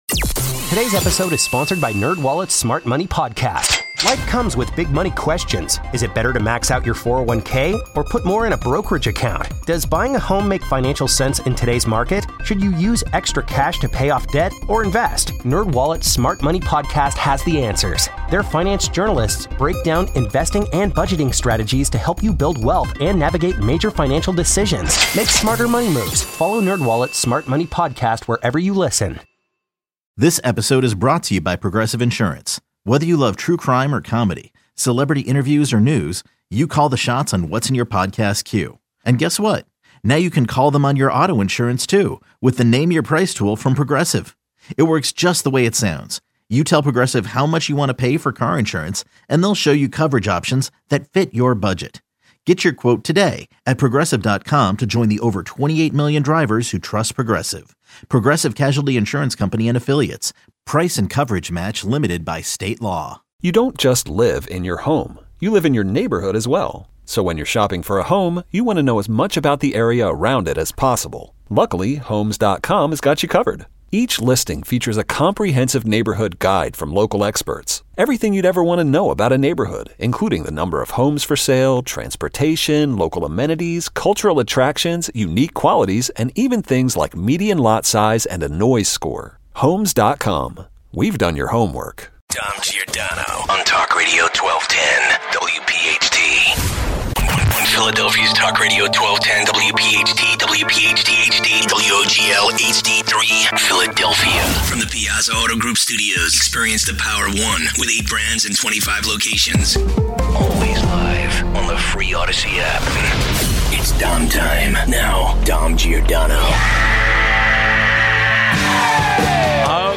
115 - Your calls.